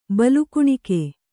♪ balu kuṇike